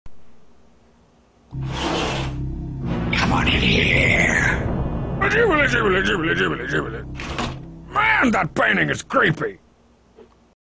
Meme Sound Effect